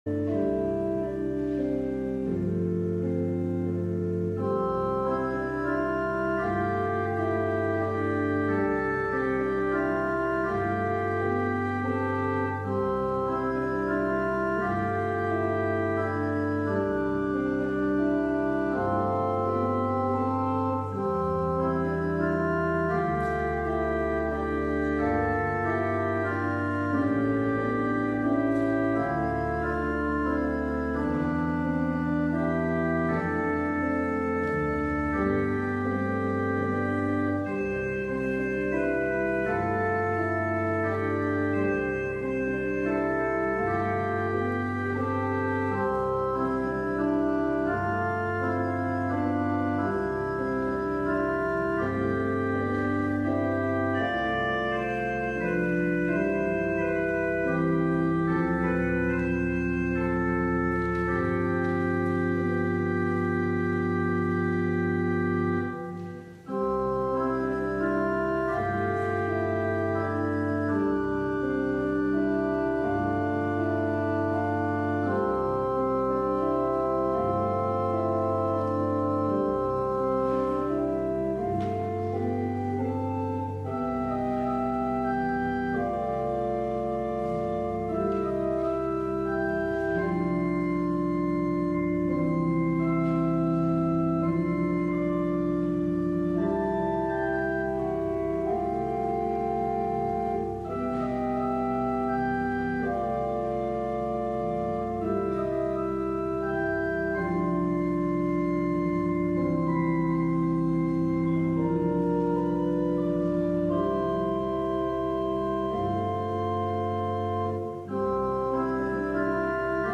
LIVE Evening Worship Service - Do You Love Me?
Congregational singing—of both traditional hymns and newer ones—is typically supported by our pipe organ.